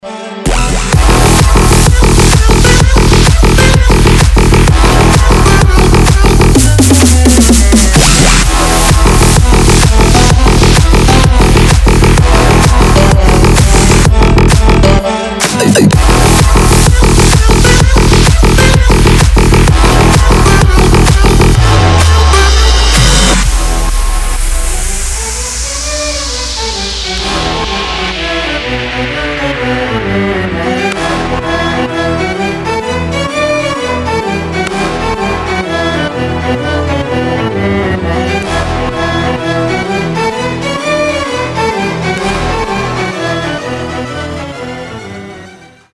• Качество: 320, Stereo
громкие
мощные
dance
Electronic
без слов
Bass